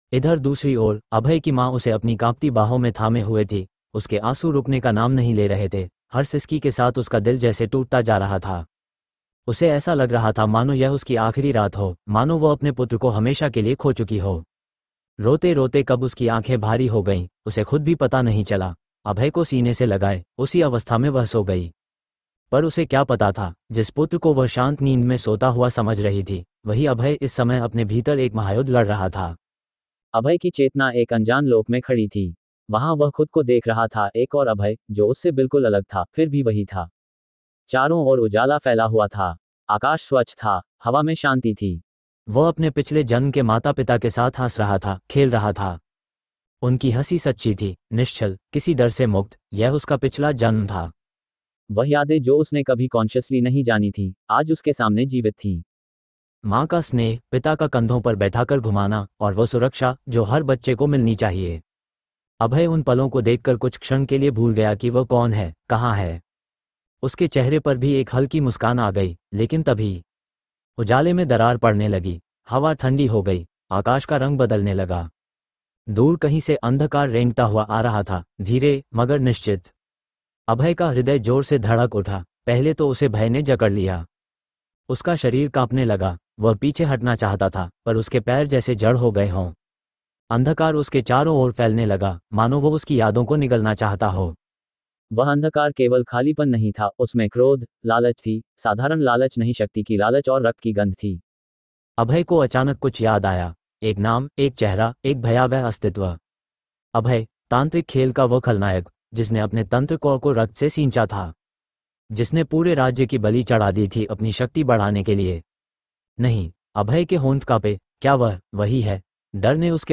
AudioTaleFM – Premium Sci-Fi, Fantasy & Fairy Tale Audio Stories